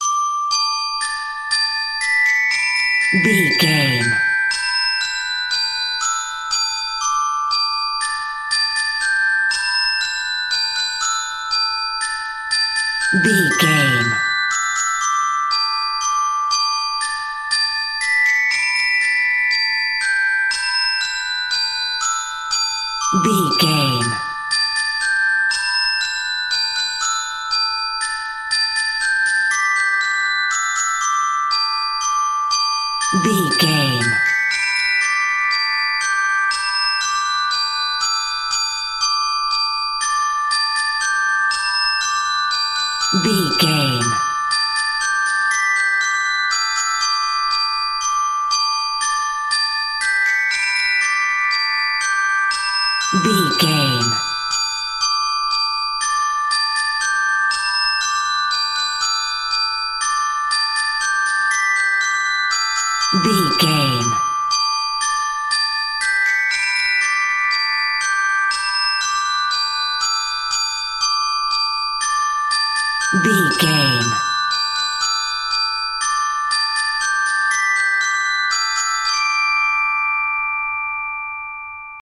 Ionian/Major
D
nursery rhymes
childrens music